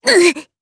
Cecilia-Vox_Damage_jp_01.wav